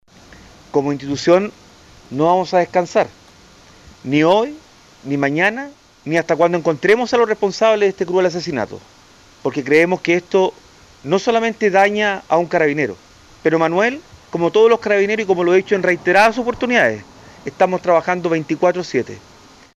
En tanto, pasada la medianoche en el Hospital de Carabineros, el general director de la institución, Ricardo Yáñez, lamentó el asesinato del joven teniente y recalcó que murió defendiendo a su familia, luego de intentar repeler un intento de robo.